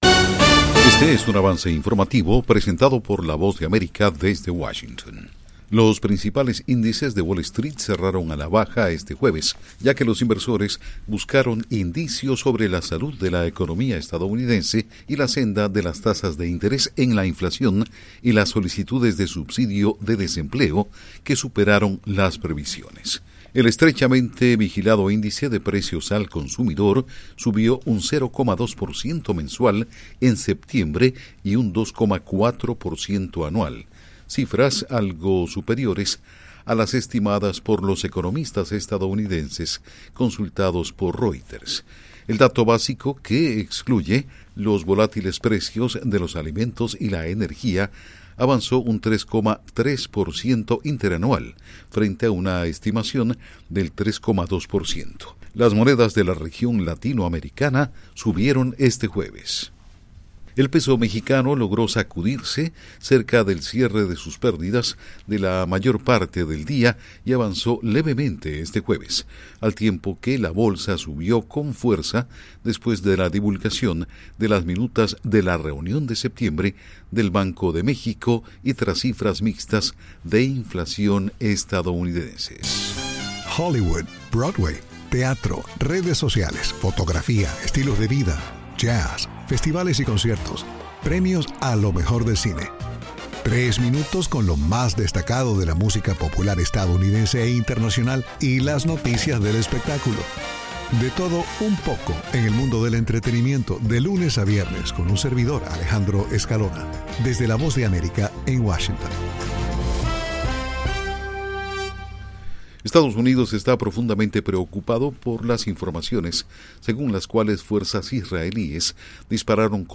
Avance Informativo 6:00 PM